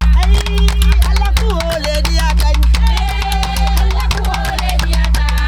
DANCE 08.A.wav